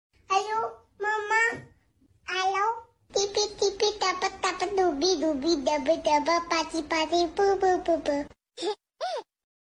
голосовые , детский голос